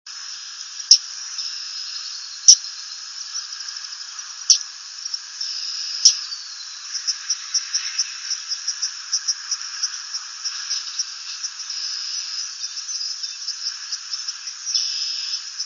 Male and female courtship calls.
yellowthroat_courtship_calls_760.wav